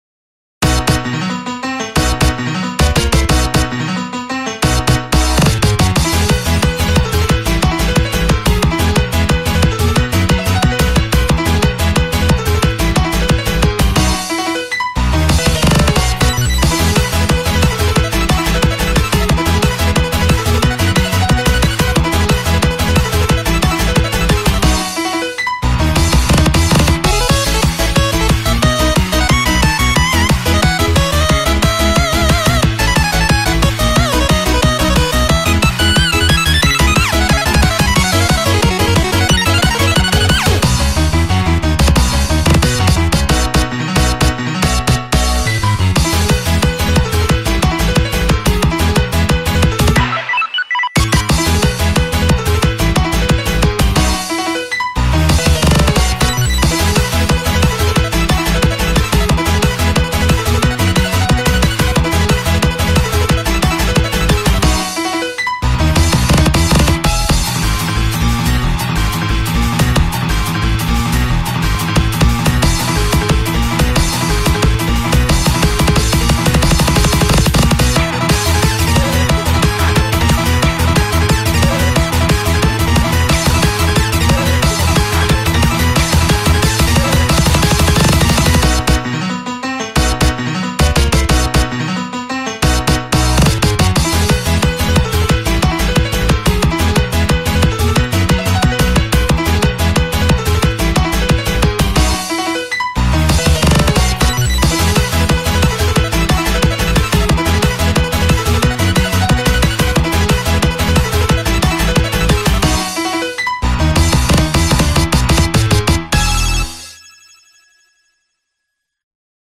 BPM90-180
Audio QualityPerfect (Low Quality)